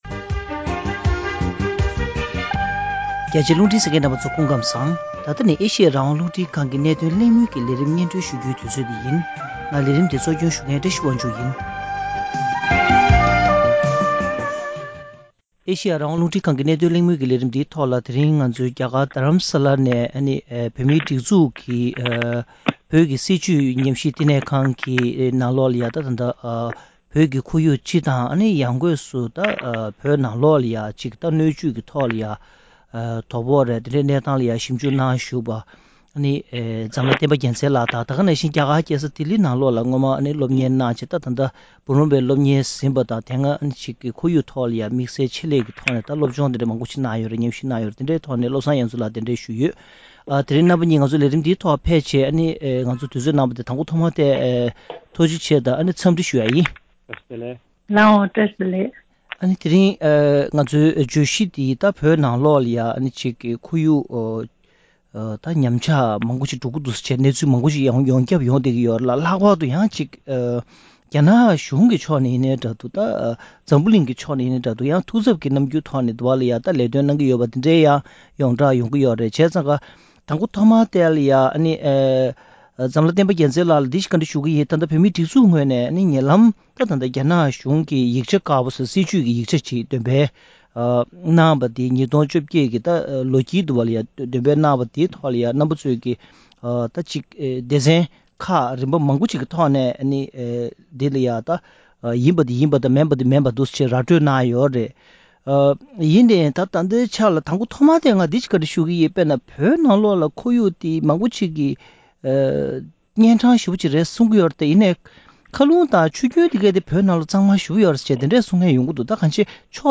བོད་ནང་གི་ཆུ་བོ་ཁ་སྒྱུར་གྱི་བྱ་སྤྱོད་དང་། དེ་མིན་གྱི་ཁོར་ཡུག་གནས་བབ་བཅས་ཀྱི་སྐོར་གླེང་མོལ་ཞུས་པ།